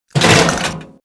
CHQ_GOON_hunker_down.mp3